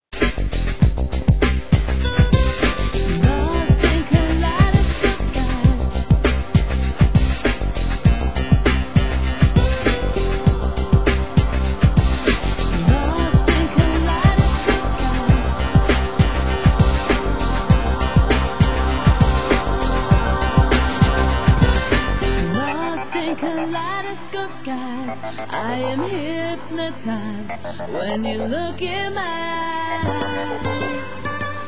Genre Euro House